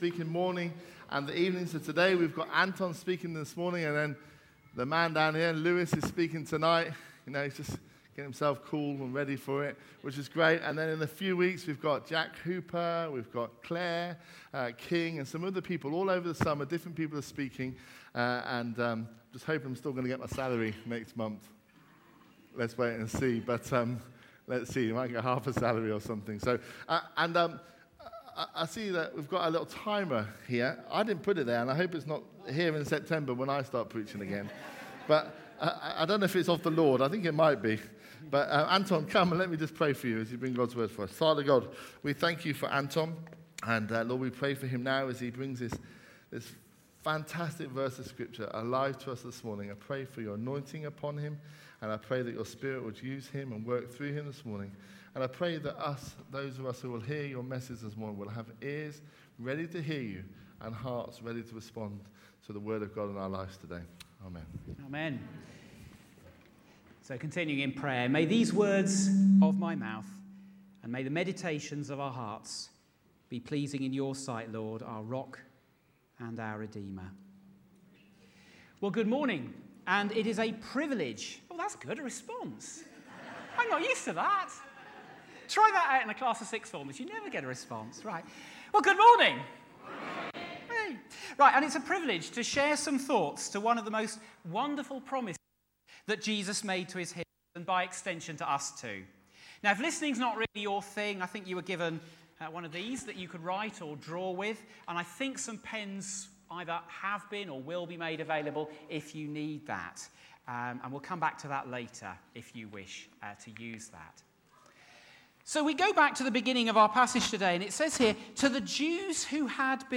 A message from the series "Promises of God."